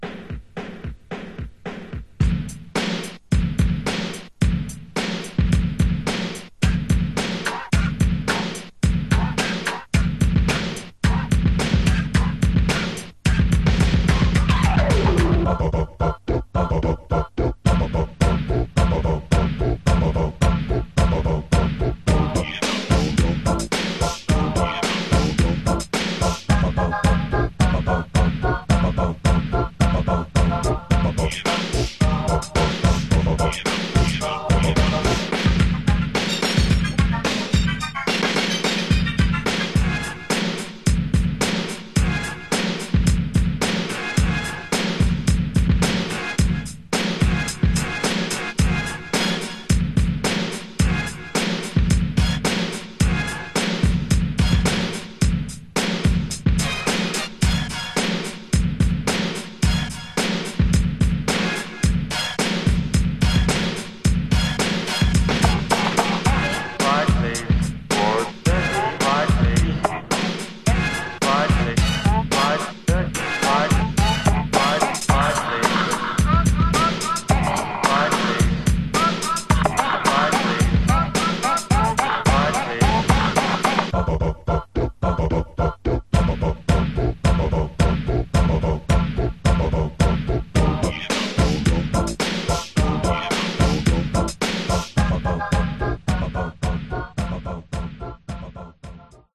Genre: Techno/Synth Pop